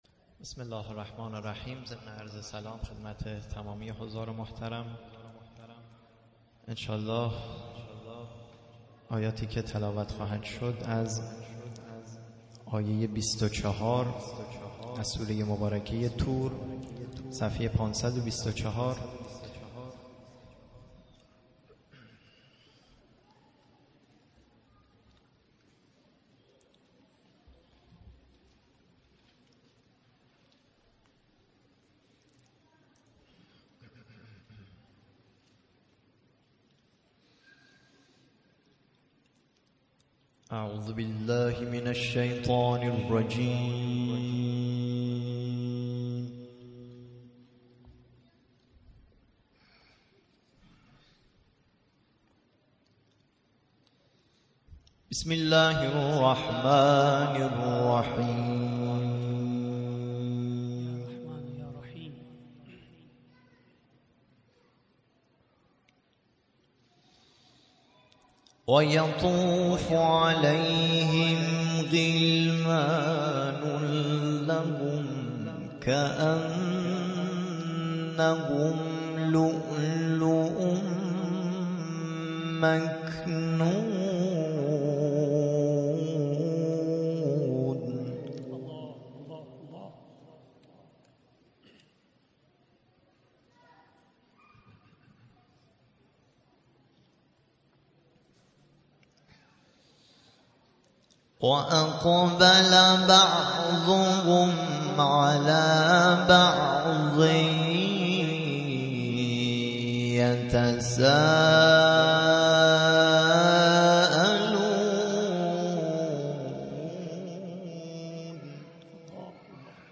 تلاوت قرآن